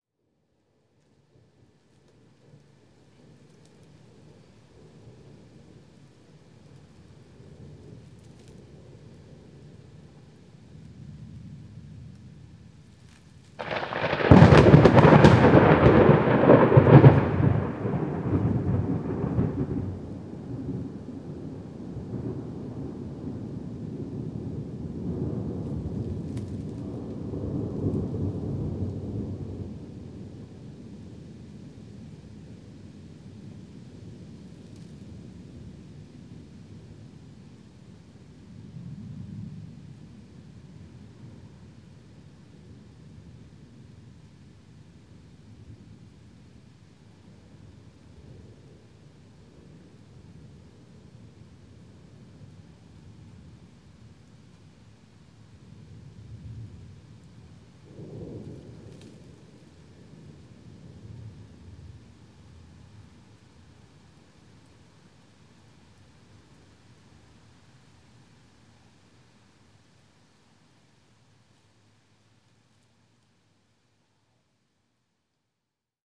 Thunder Clap OWB KY 441x16
Category 🌿 Nature
Clap Field-Recording low rumble Single Thunder sound effect free sound royalty free Nature